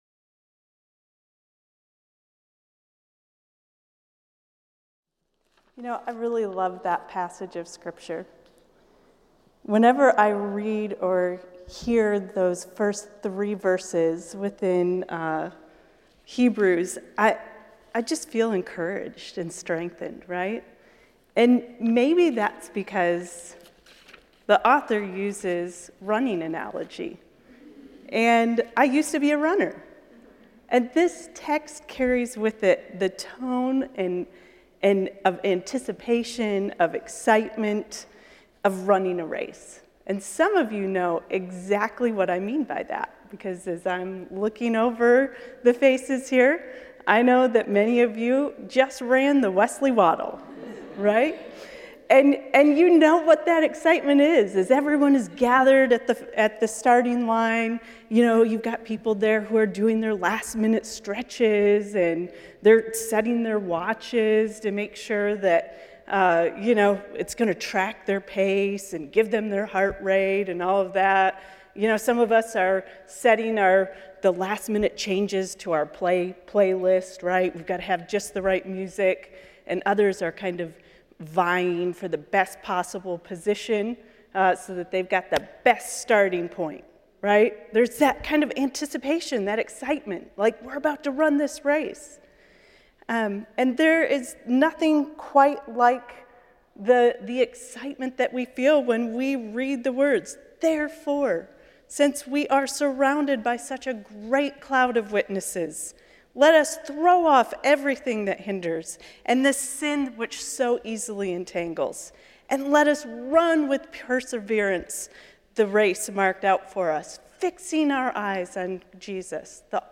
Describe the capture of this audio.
The following service took place on Wednesday, April 26, 2023.